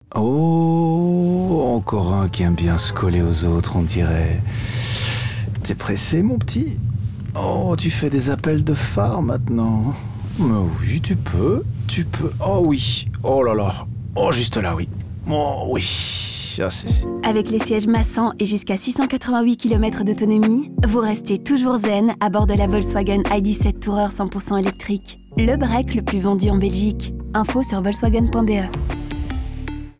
C'est exactement ce qui se passe dans ces spots publicitaires : ils nous parlent de cette rage au volant, mais en sortant des sentiers battus. Certes, les conducteurs expriment crûment leur colère envers les autres automobilistes... mais ils le font d'un ton étonnamment calme, presque béat.
Le contraste ludique entre les propos peu amènes et la manière détendue dont ils sont prononcés montre parfaitement comment l'ID.7 transforme même les situations les plus tendues en un pur moment de zenitude.